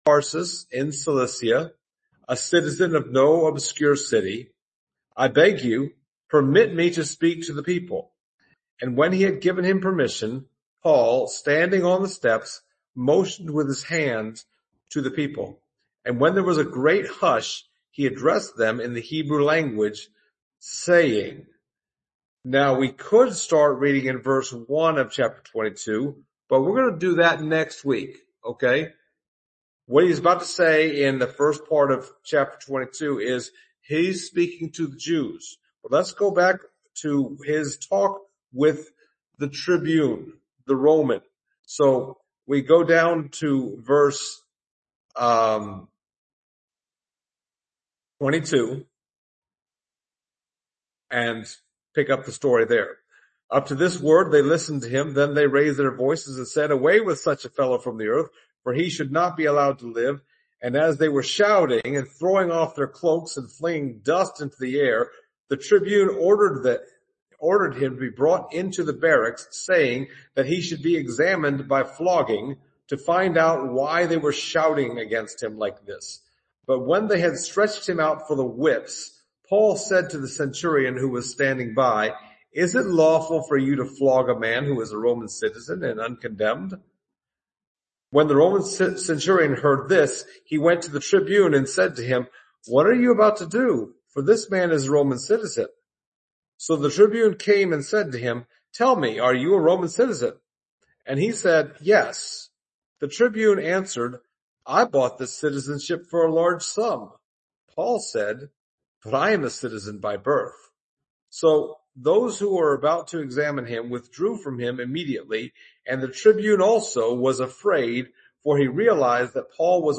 Passage: Acts 21:37-40; 22:22-29 Service Type: Sunday Morning